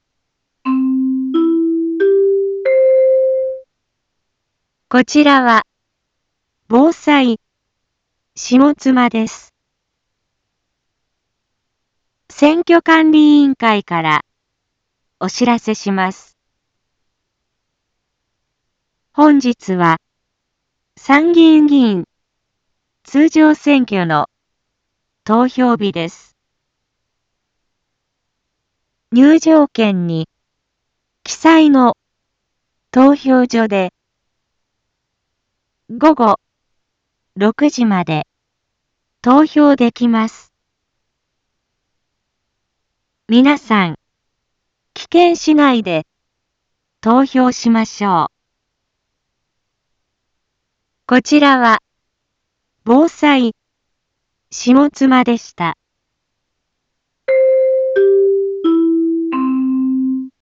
一般放送情報
Back Home 一般放送情報 音声放送 再生 一般放送情報 登録日時：2025-07-20 16:31:20 タイトル：参議院議員通常選挙の啓発（投票日） インフォメーション：こちらは、ぼうさいしもつまです。